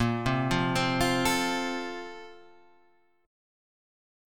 A#M7sus2 chord